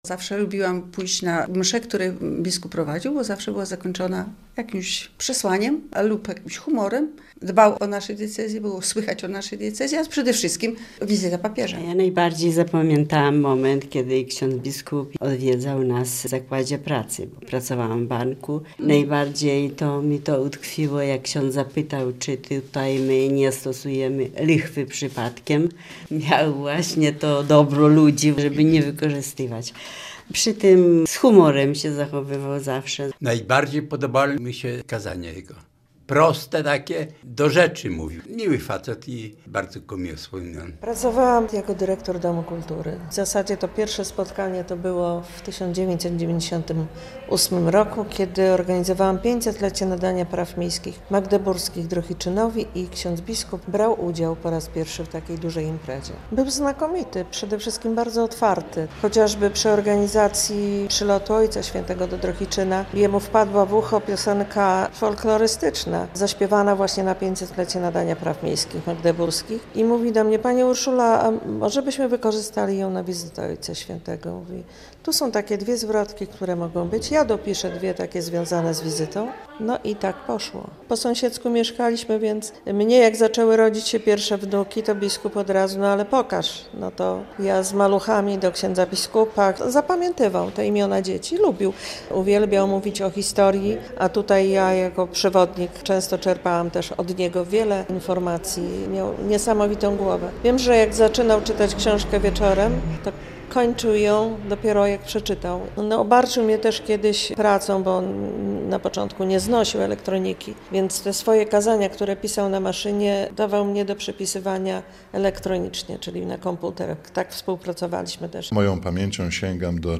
Mieszkańcy Drohiczyna wspominają biskupa Antoniego Dydycza - relacja